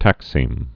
(tăksēm)